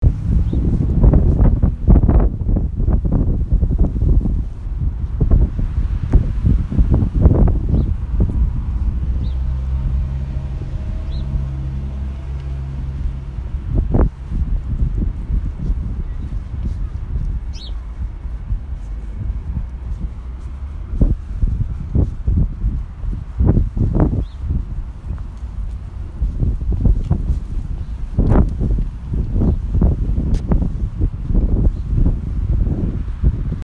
Field Recording Six
Location: The pathway to the student center.
Wind whooshing into the microphone, my footsteps on the cement path, a bird chirping as I walk by, and cars driving by in the distance.